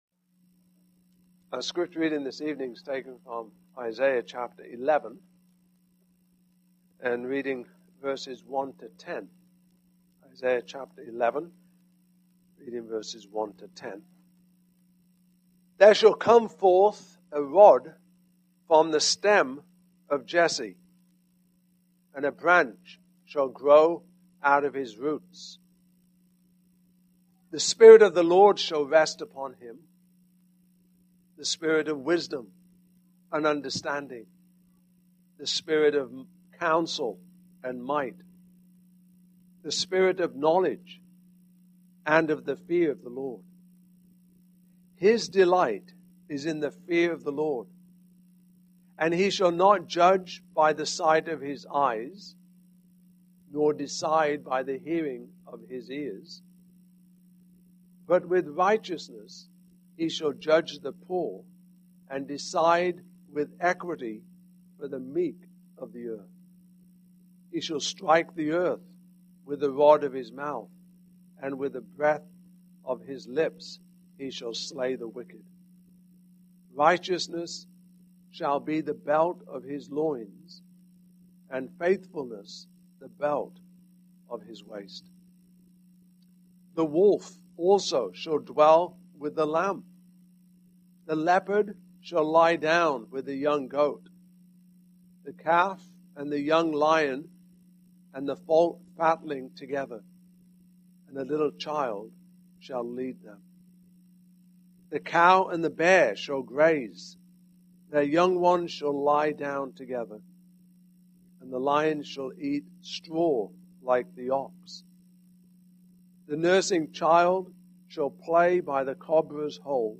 Passage: Isaiah 11:1-10 Service Type: Evening Service